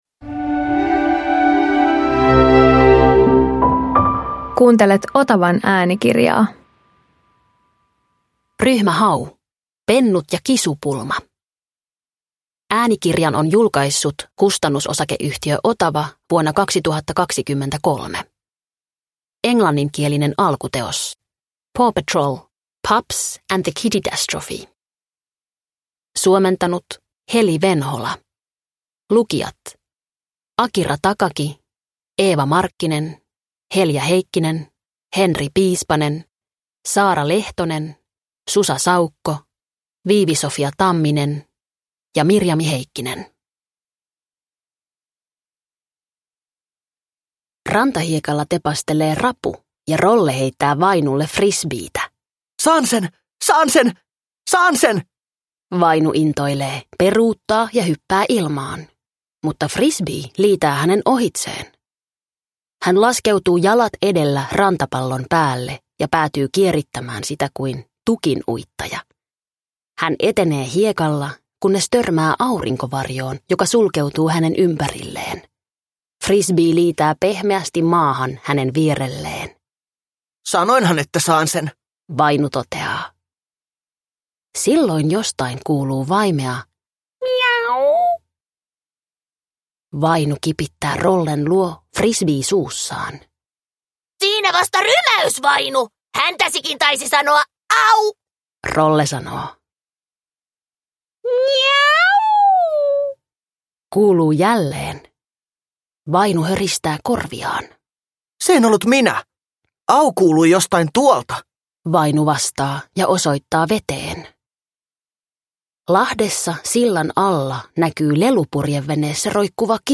Ryhmä Hau Pennut ja kisupulma – Ljudbok – Laddas ner